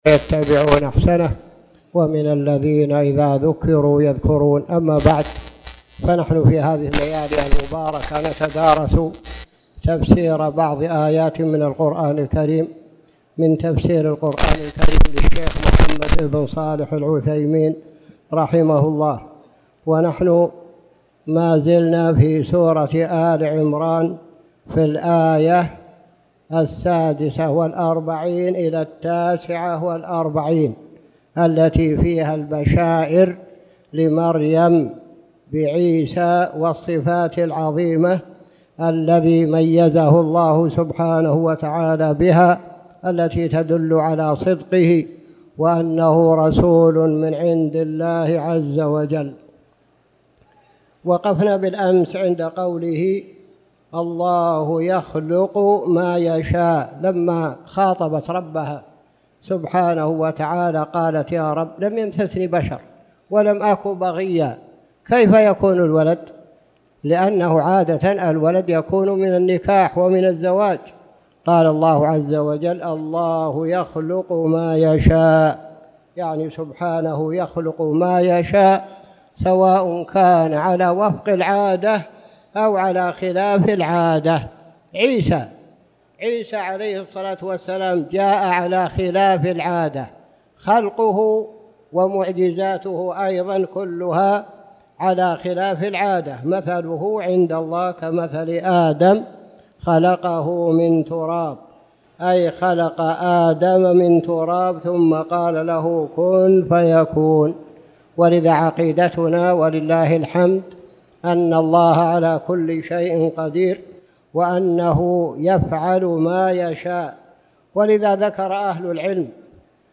تاريخ النشر ٢٢ جمادى الأولى ١٤٣٨ هـ المكان: المسجد الحرام الشيخ